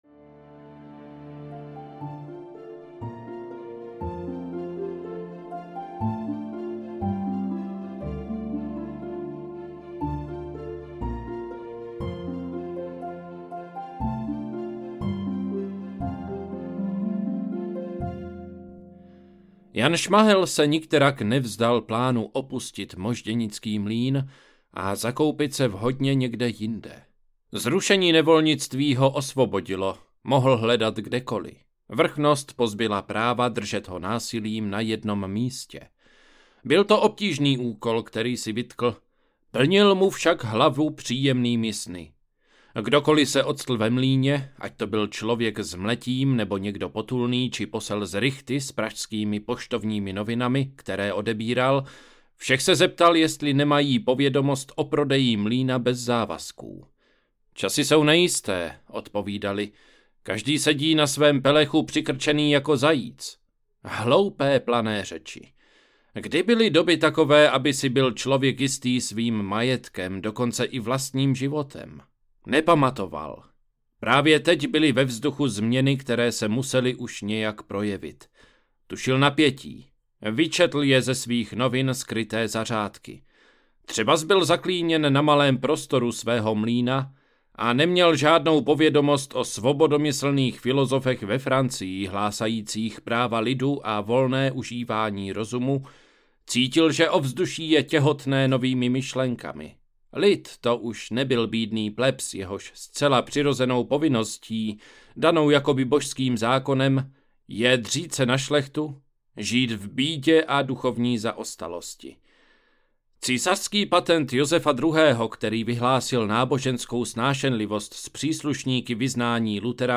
Stopy mých otců audiokniha
Ukázka z knihy